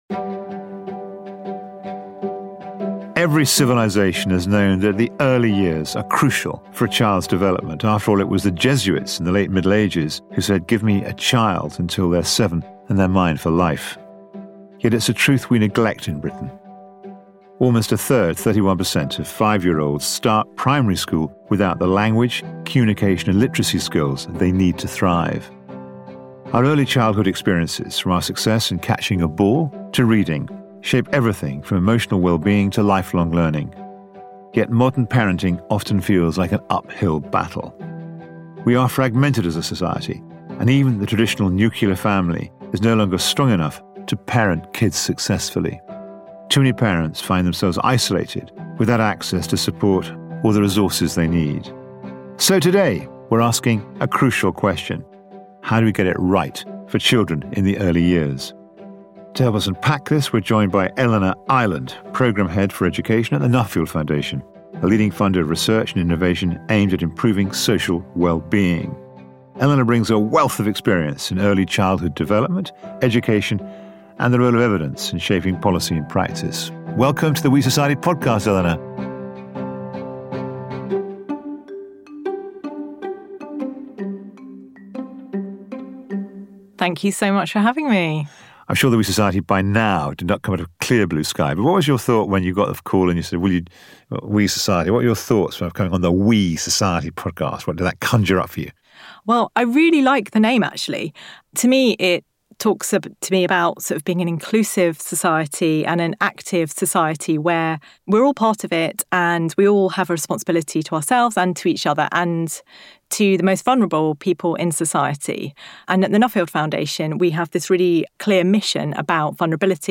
In the We Society, join acclaimed journalist and Academy president Will Hutton, as he invites guests from the world of social science to explore the stories behind the news and hear their solutions to society’s most pressing problems.